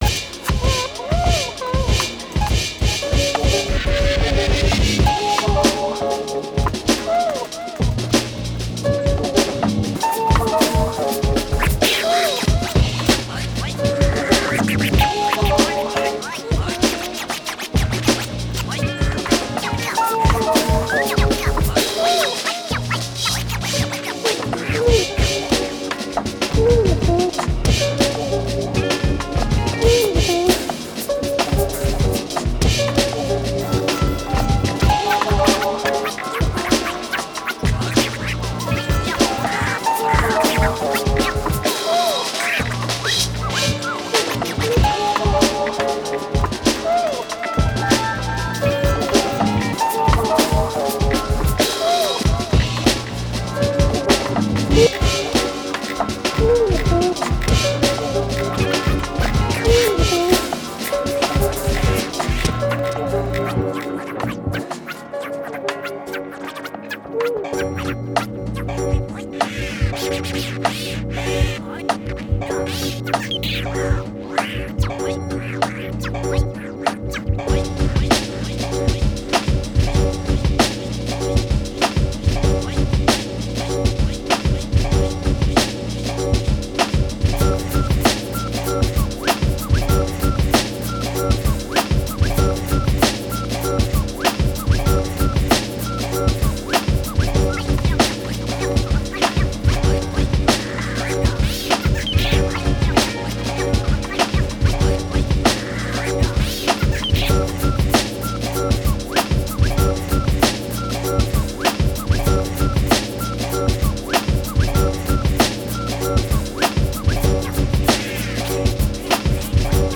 Practiced my scatches on this one a little bit.
Love the breakdown near the end :ok_hand: